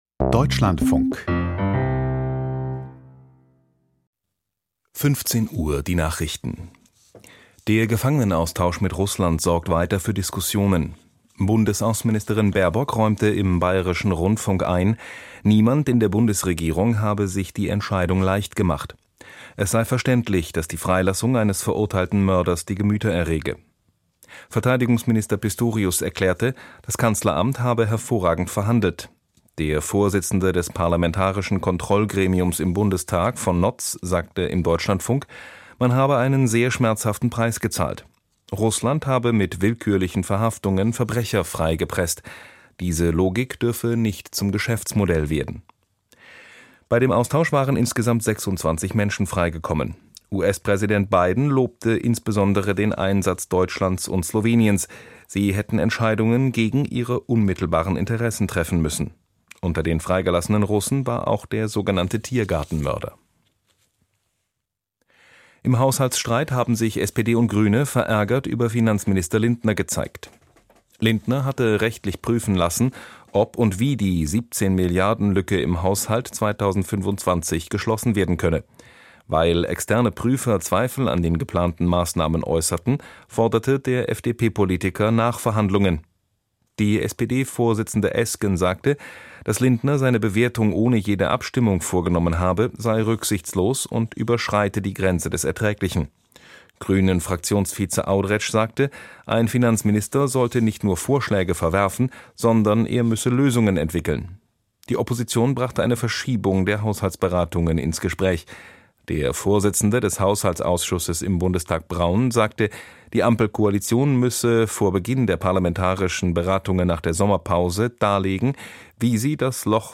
Azubis: Branchen suchen verstärkt im Ausland - Interview